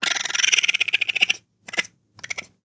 Waschbär Laute und ihre Vielfalt
Die Waschbär Laute reichen von Knurren und Zischen bis zu hohen Quietschtönen.
Waschbär Geräusche
Waschbaer-Geraeusche-Wildtiere-in-Europa.wav